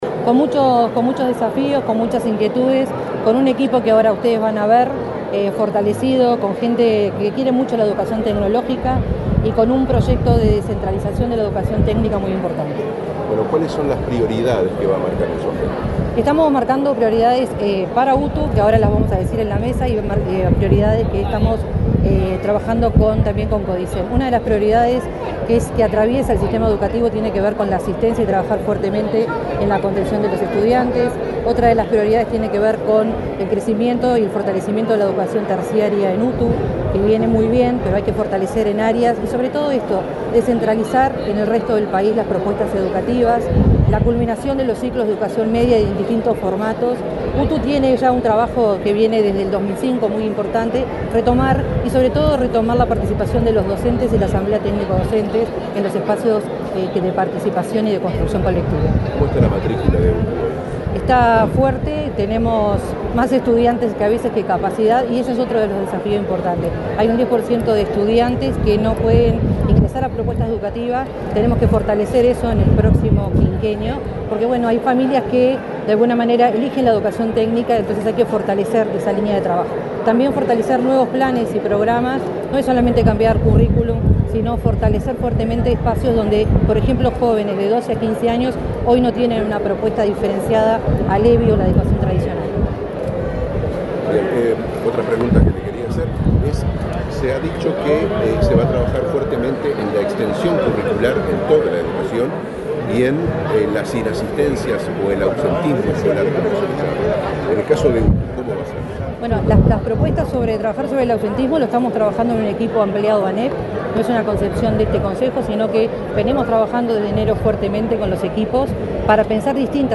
Declaraciones de la directora general de Educación Técnico-Profesional, Virginia Verderese
Declaraciones de la directora general de Educación Técnico-Profesional, Virginia Verderese 01/04/2025 Compartir Facebook X Copiar enlace WhatsApp LinkedIn La titular de la Dirección General de Educación Técnico-Profesional (DGETP-UTU), Virginia Verderese, dialogó con la prensa acerca de los lineamientos de su gestión, antes de tomar posesión del cargo al frente de esa repartición pública educativa.